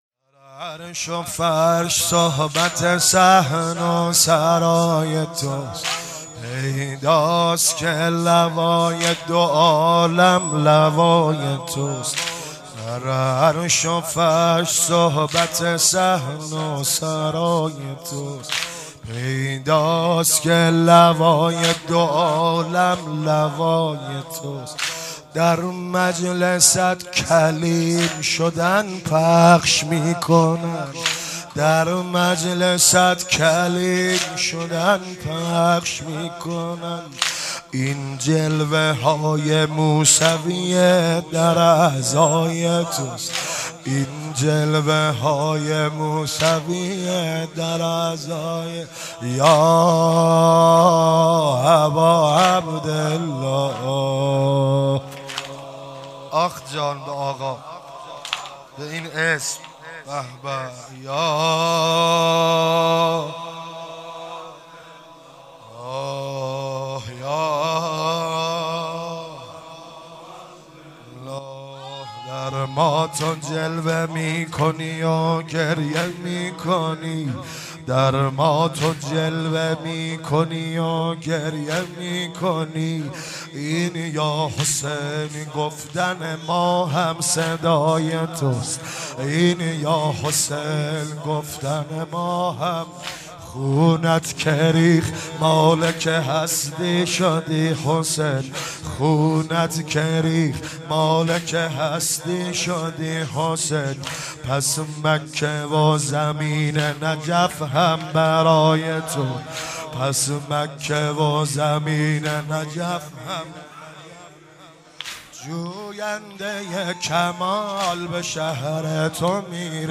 شهادت امام صادق شب دوم 96 -واحد - در عرش و فرش صحبت
واحد مداحی